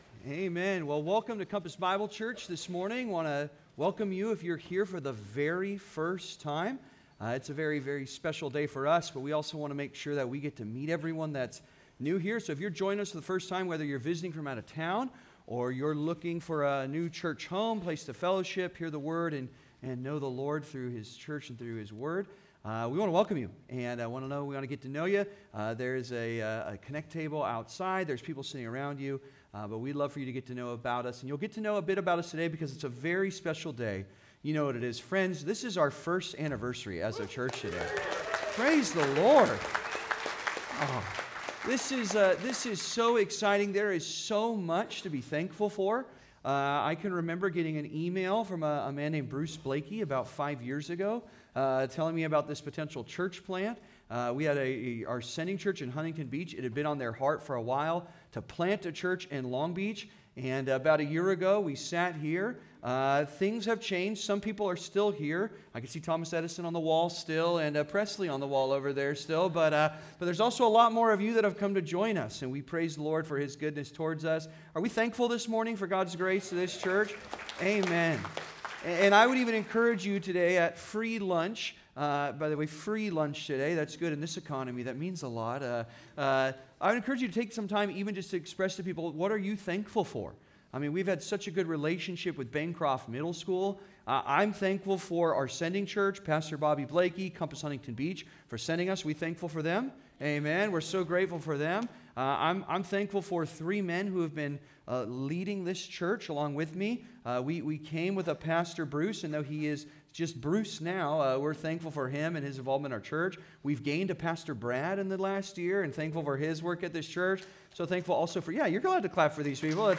Knowing, Growing, Keep Going (Sermon) - Compass Bible Church Long Beach